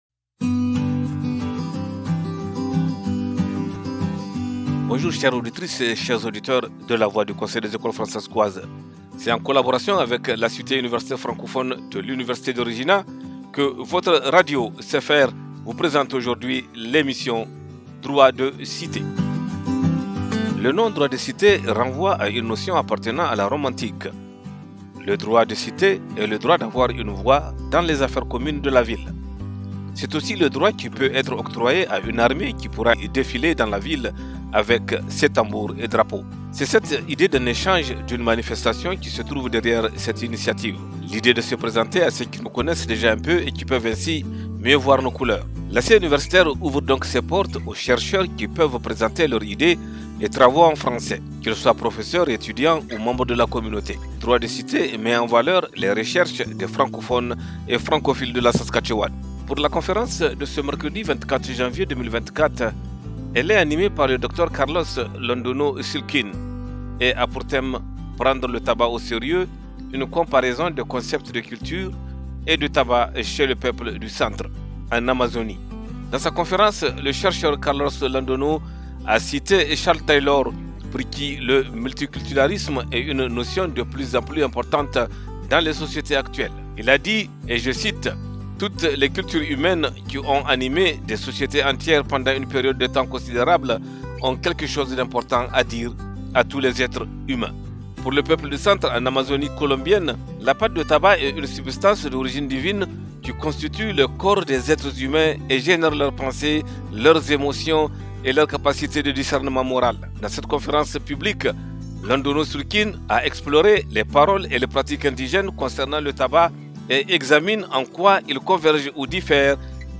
La série des midi-conférences Droit de Cité met en valeur les recherches des francophones et francophiles de la Saskatchewan.